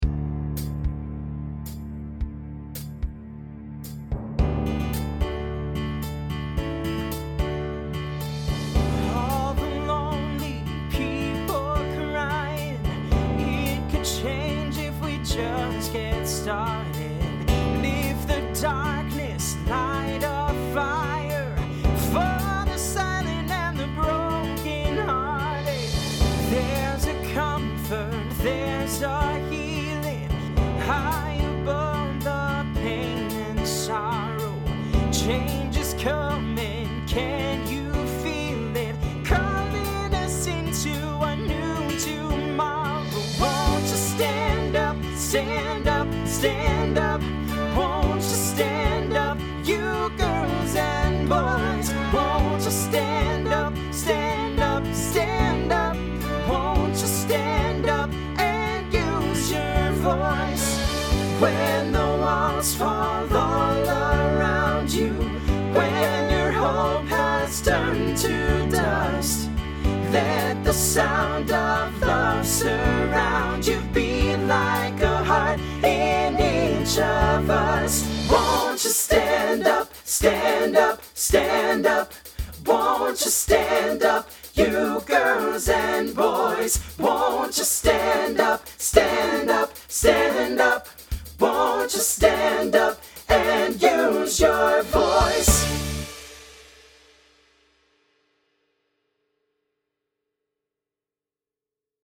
Voicing SATB Instrumental combo Genre Country
Mid-tempo